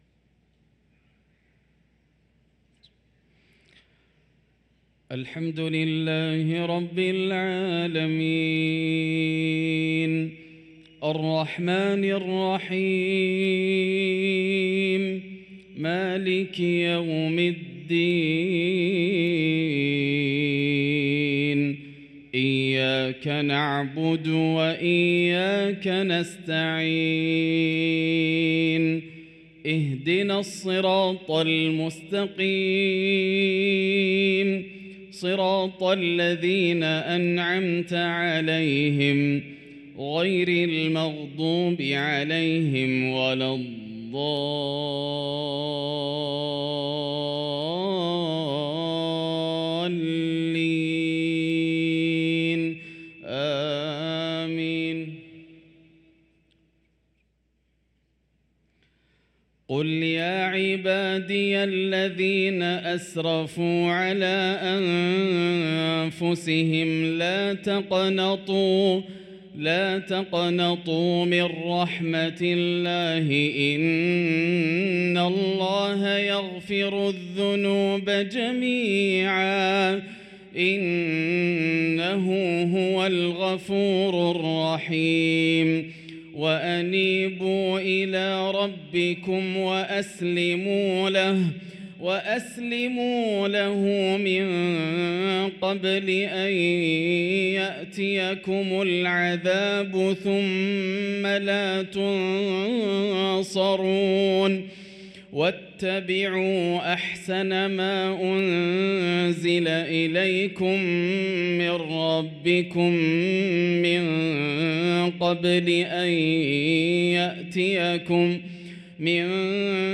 صلاة العشاء للقارئ ياسر الدوسري 19 صفر 1445 هـ